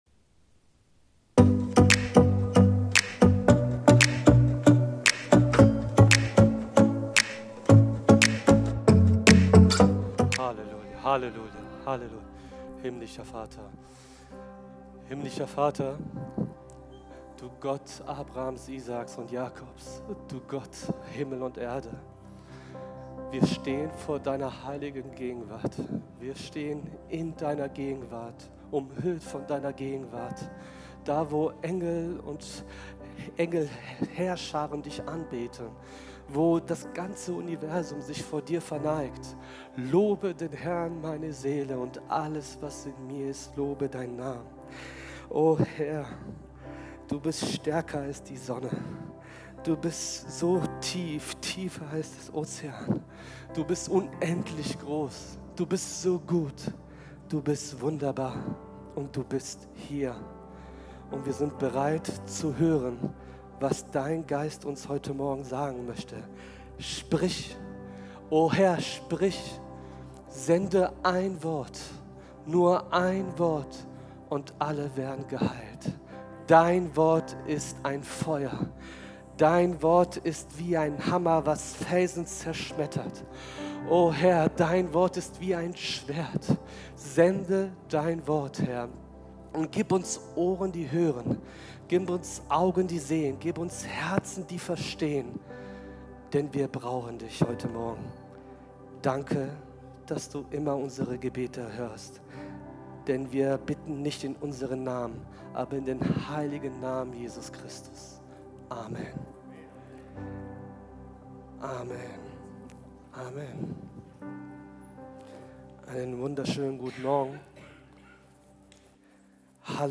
Live-Gottesdienst aus der Life Kirche Langenfeld.
Kategorie: Sonntaggottesdienst Predigtserie: Freunde fürs Leben - Wie gute Beziehungen gelingen